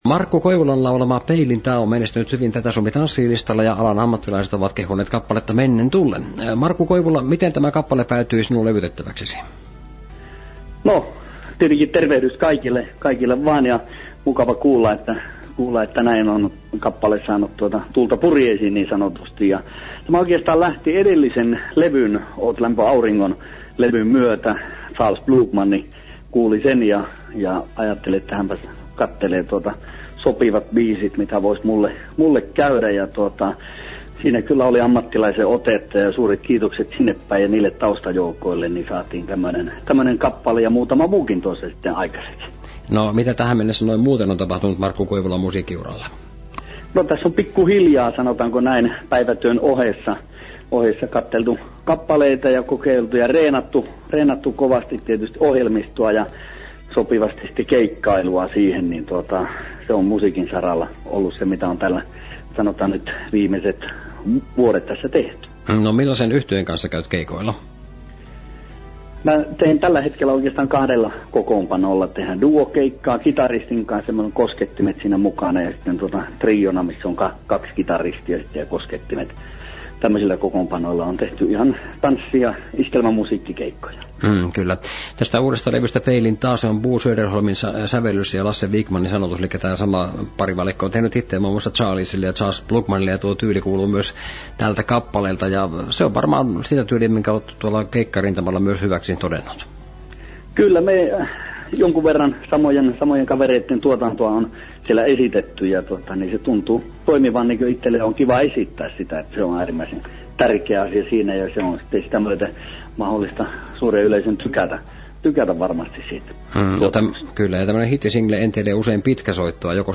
radiohaastattelu.mp3